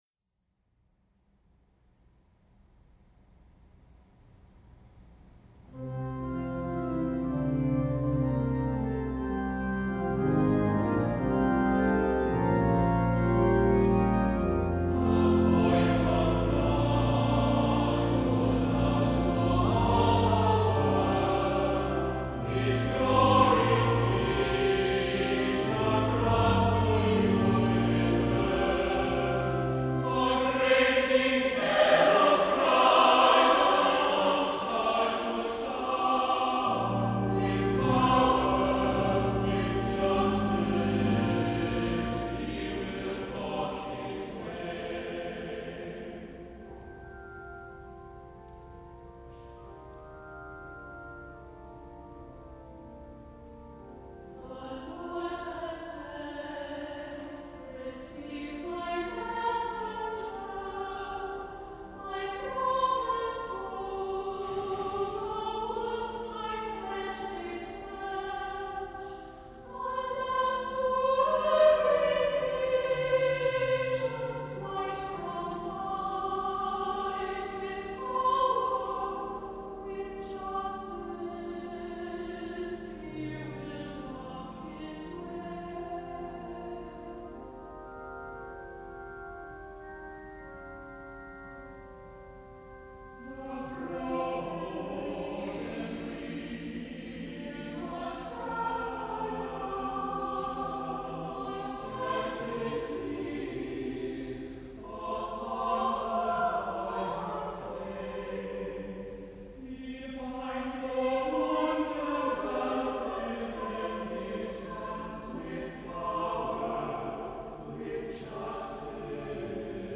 * soaring descant (modest range) for final verse